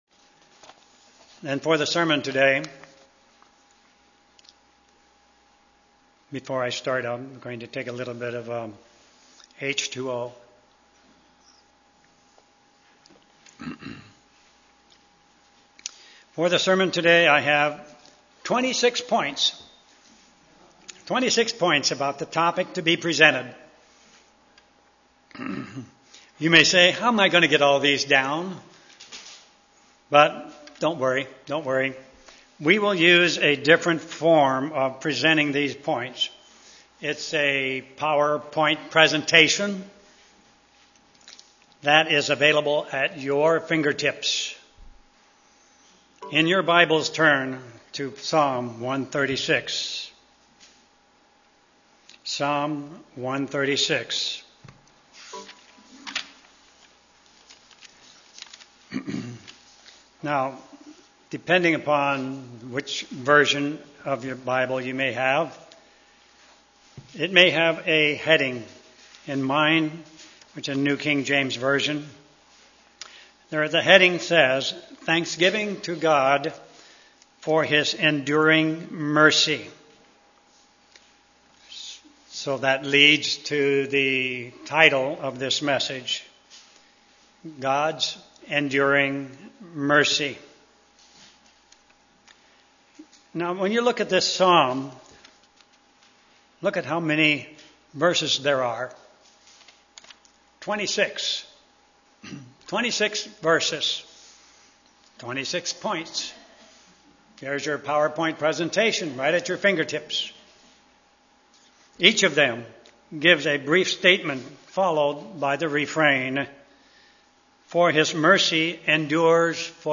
Given in Olympia, WA